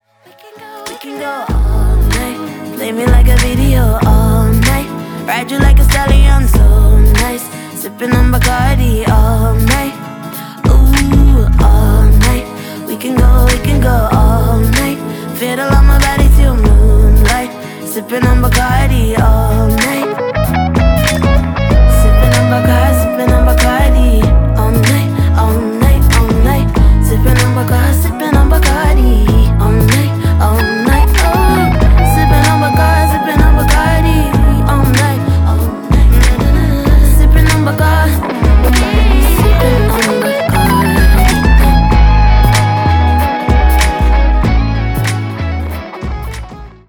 • Качество: 320, Stereo
dance
спокойные
Dance Pop
красивый женский голос